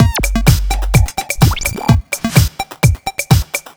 127 Blip Jam Full.wav